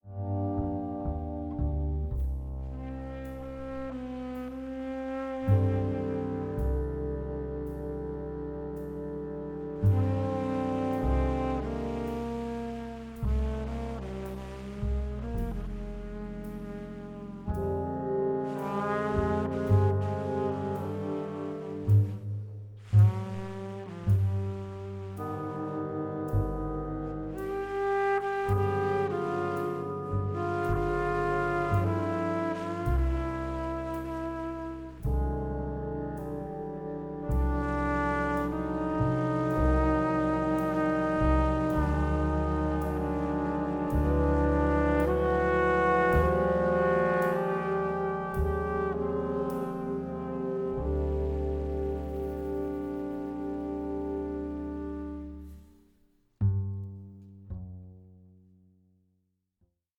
the versatile reed players
the reed quintet.